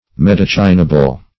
Search Result for " medicinable" : The Collaborative International Dictionary of English v.0.48: Medicinable \Me*dic"i*na*ble\, a. Medicinal; having the power of healing.